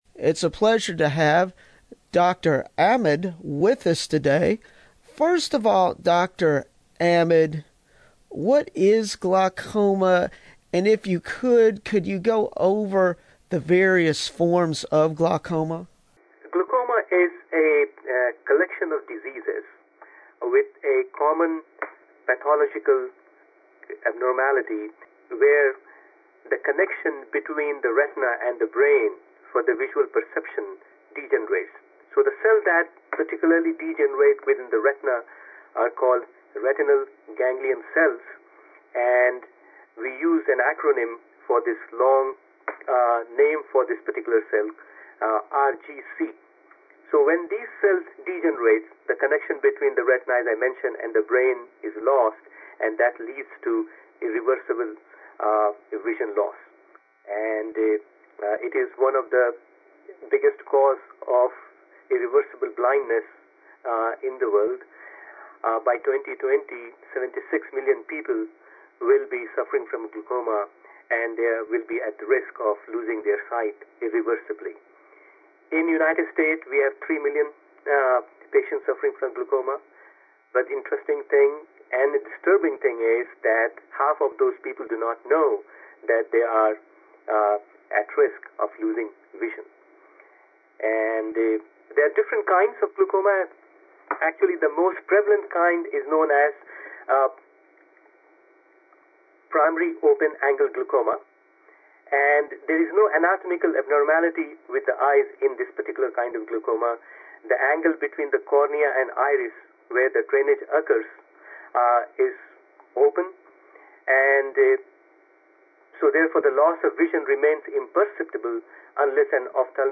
WYPL FM 89.3: Radio Station interview